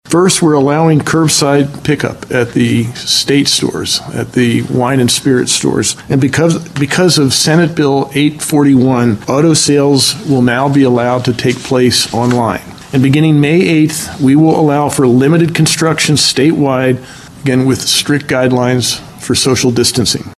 Wolf talks about small steps the state is taking toward returning to a degree of normalcy with auto sales, construction and state stores.